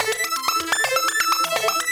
Index of /musicradar/shimmer-and-sparkle-samples/125bpm
SaS_Arp03_125-A.wav